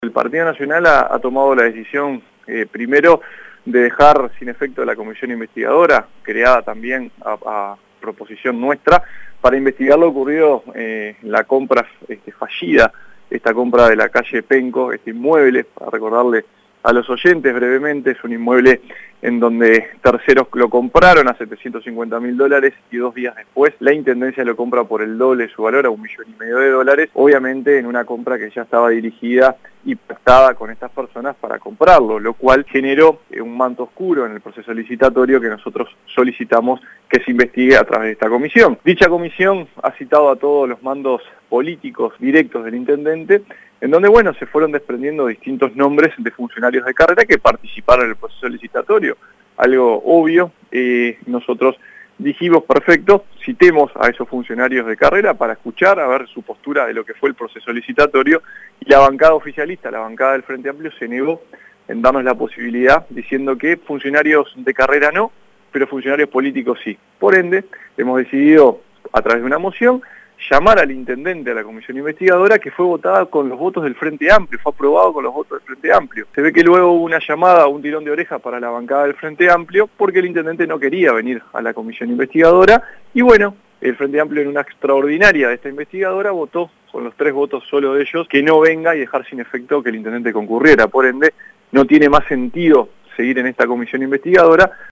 dijo el Edil Diego Rodríguez a Informativo Universal